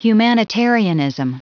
Prononciation du mot humanitarianism en anglais (fichier audio)
Prononciation du mot : humanitarianism